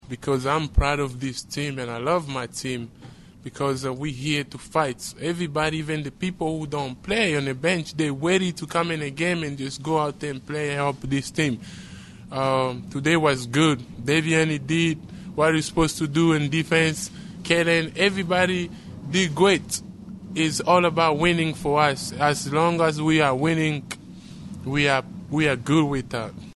Oscar Tshiebwe Postgame